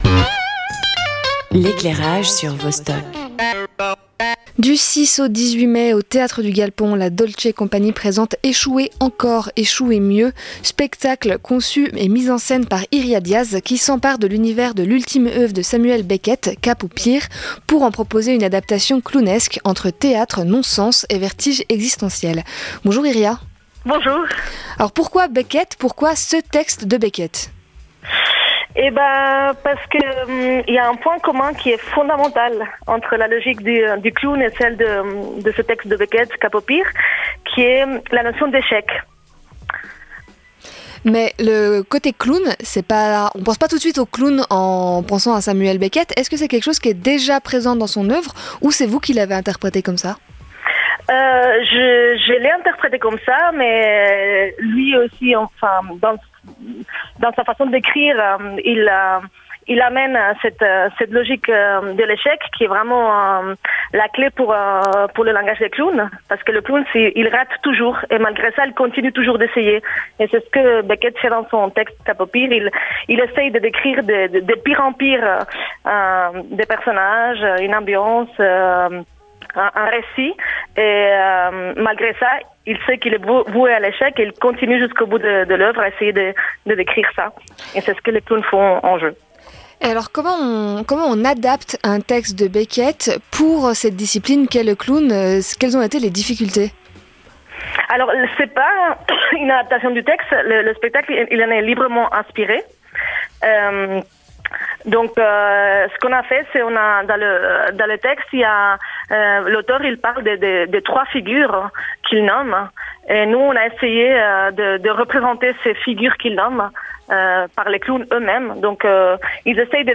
Invitée
Animation